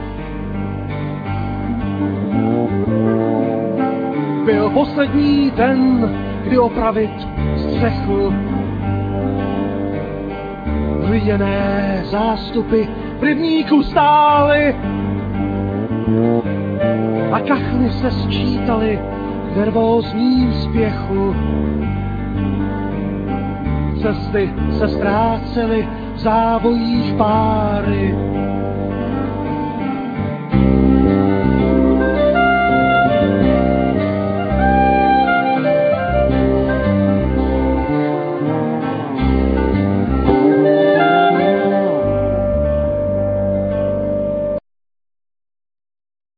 Vocal,Trumpet,Tuboflaut,Backing vocal
Piano,Keyboard,Cymbals,Backing vocal
Drums,Persussions
Clarinet,Bariton sax,Alt sax,Backing vocal
Double bass